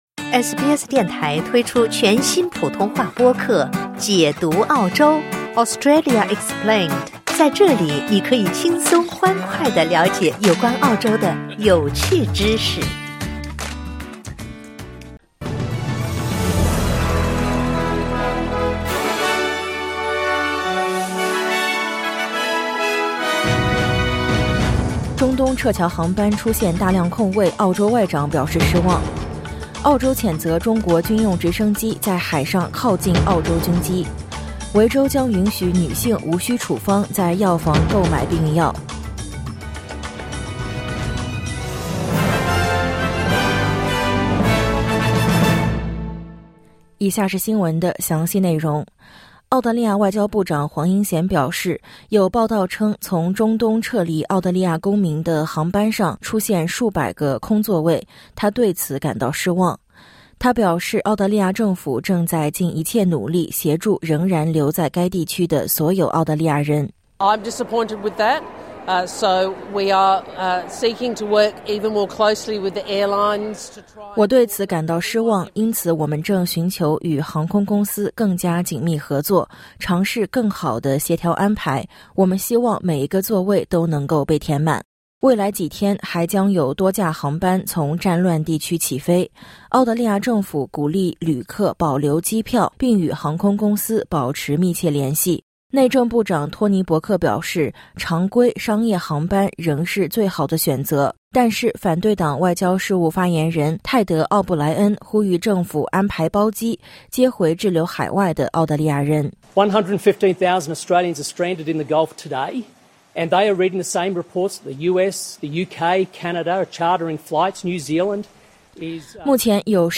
【SBS早新闻】澳洲谴责中国军机在海上靠近澳军机